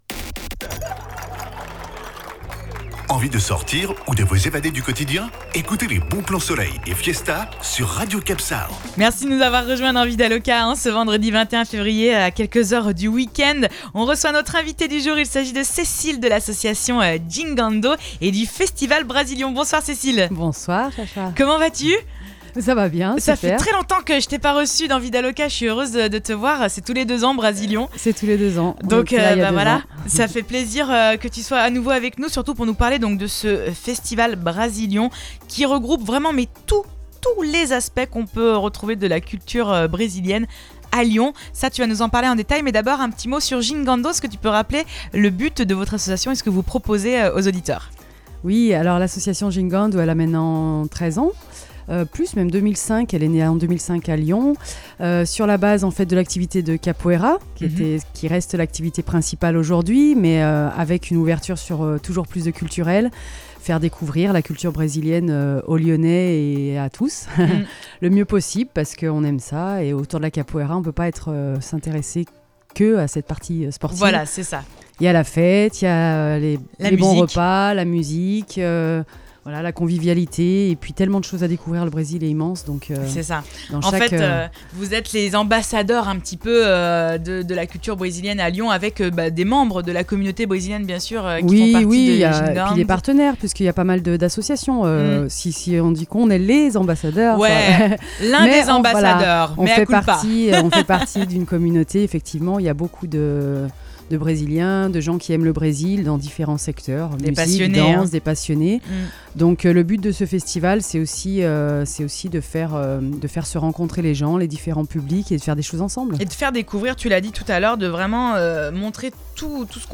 Le Festival présenté sur Radio CapSão - Emission Vida Loca